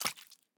minecraft / sounds / mob / frog / eat1.ogg
eat1.ogg